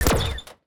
UIClick_Menu Laser Hit Rustle Tail 04.wav